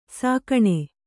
♪ sākaṇae